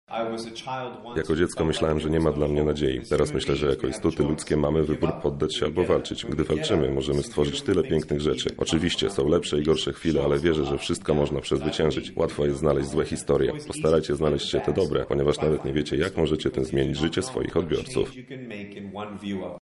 Swoje słowa skierował także do dziennikarzy